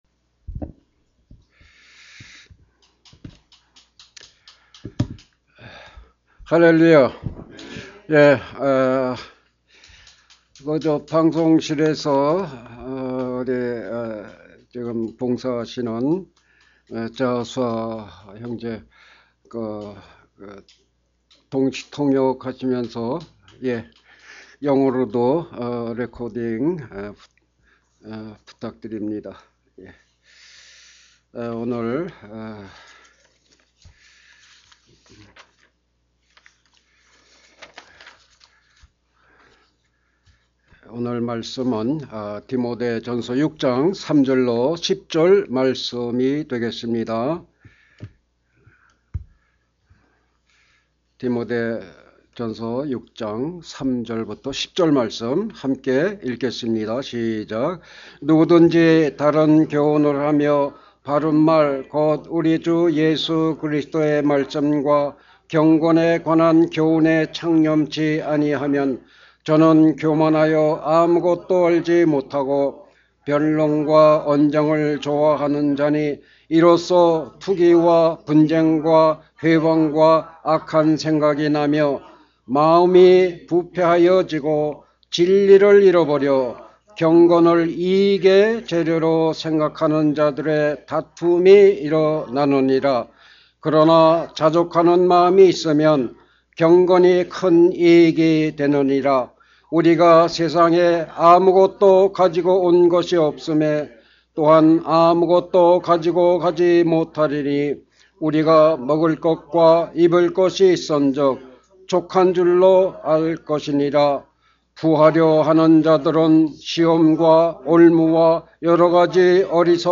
Sermon - 족한줄로 아는 자족하는 마음 A contented mind that knows what is enough